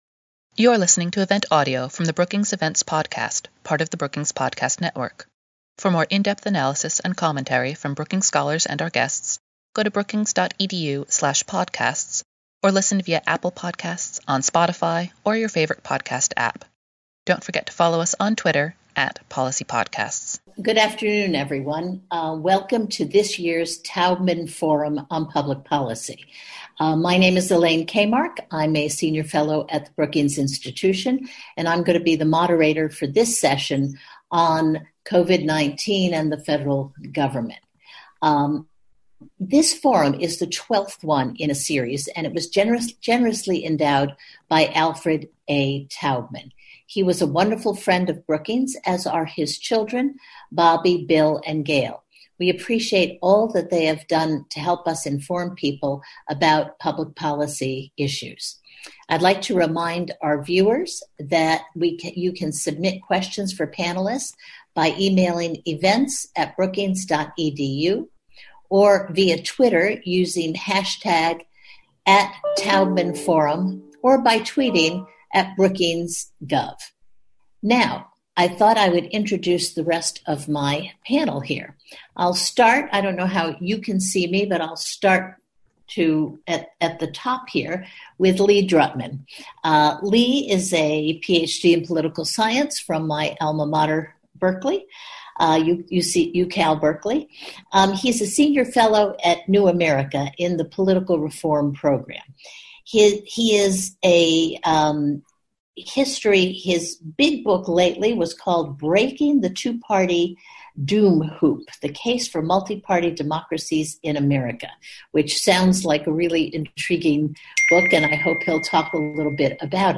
Webinar: How to reform American government | Brookings
On May 5, as part of the twelfth annual A. Alfred Taubman Forum on Public Policy, Governance Studies at Brookings hosted a webinar to look at problems of American government and possible structural and institutional reforms.
Speakers discussed how to improve government performance and how to mitigate the effects of disinformation and polarization—all obstacles that hinder good governance.